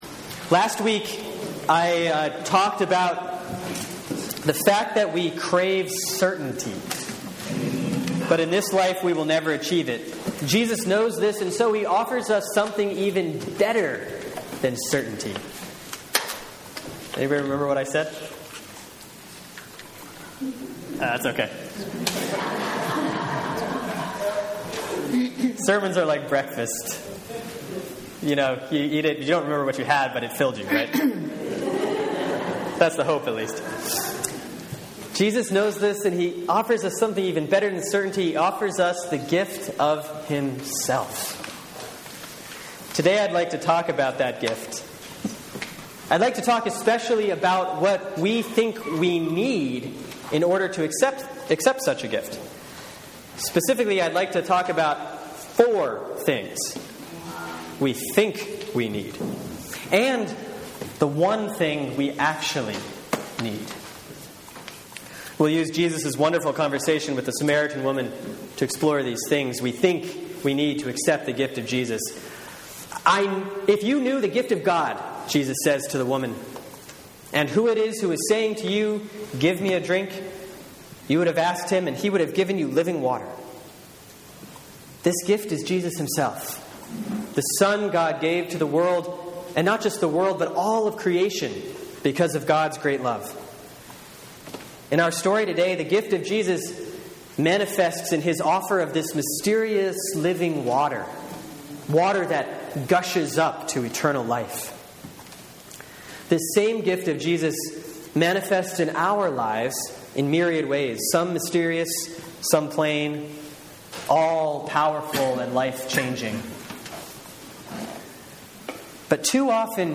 Sermon for Sunday, March 23, 2014 || Lent 3A || John 4:5-42